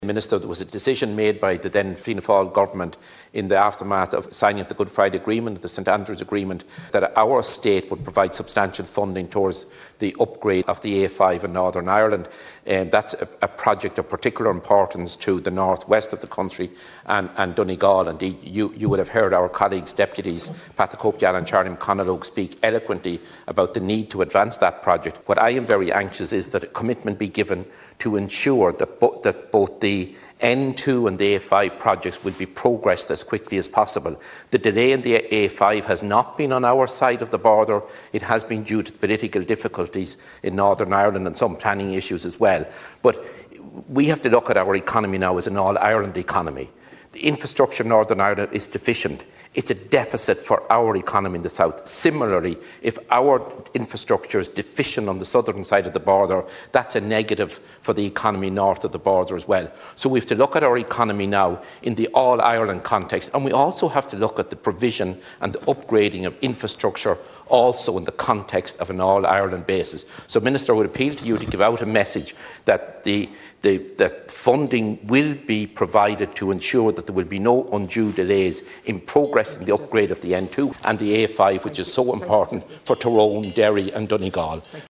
Deputy Smith appealed to the Minister to ensure there are no delays in the funding being made available when delays due to a public enquiry in the North are overcome: